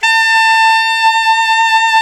Index of /90_sSampleCDs/Roland L-CDX-03 Disk 1/SAX_Alto 414/SAX_Alto mp 414
SAX ALTOMP0N.wav